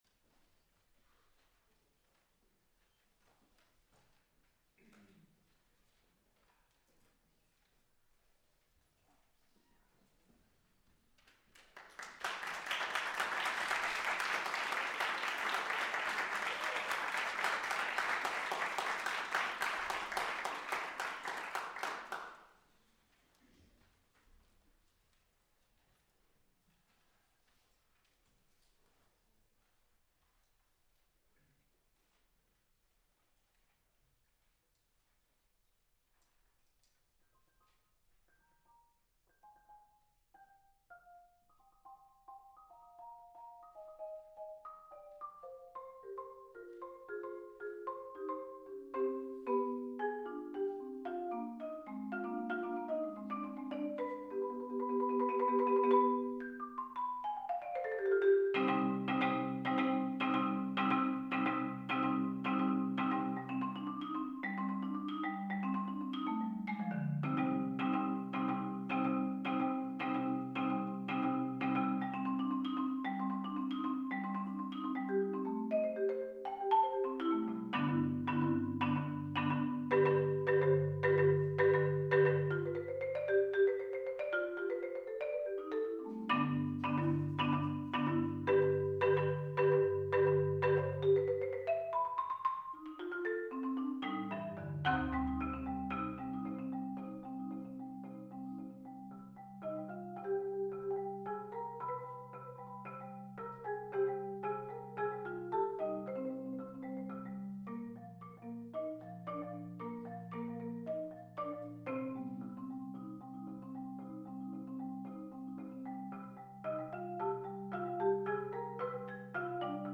Genre: Marimba (4-mallet)
Marimba (5-octave)